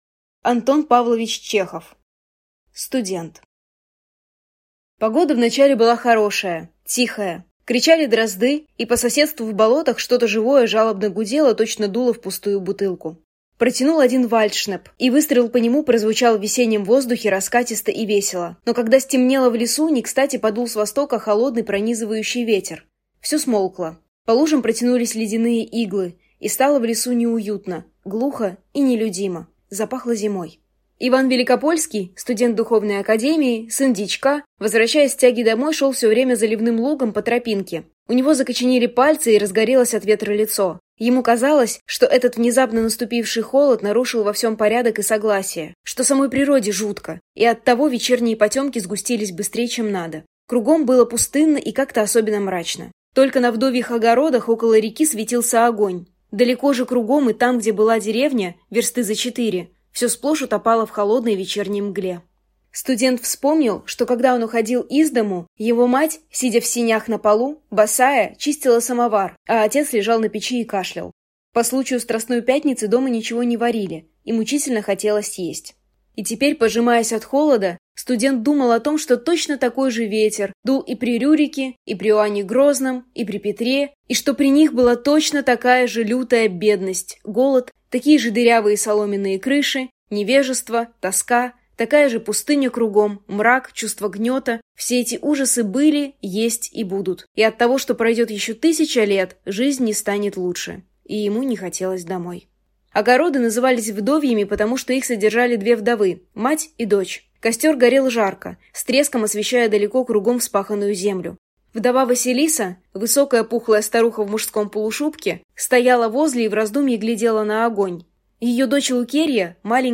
Аудиокнига Студент | Библиотека аудиокниг